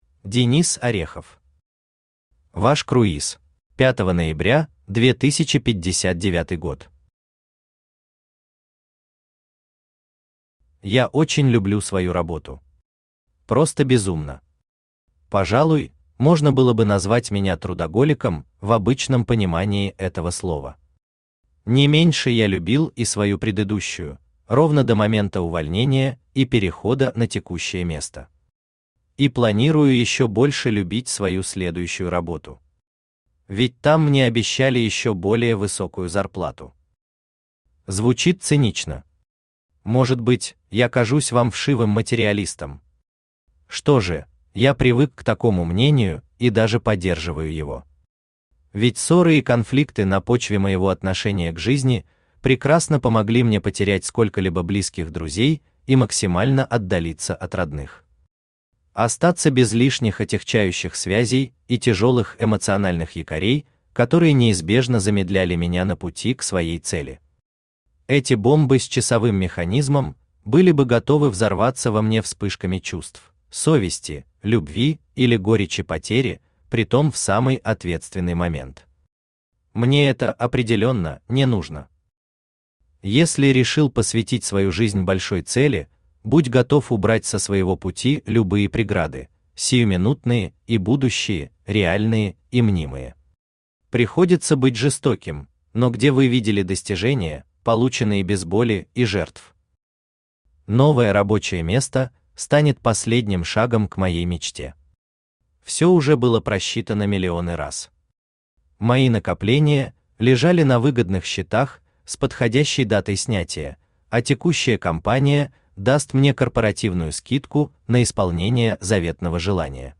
Аудиокнига Ваш круиз | Библиотека аудиокниг
Aудиокнига Ваш круиз Автор Денис Орехов Читает аудиокнигу Авточтец ЛитРес.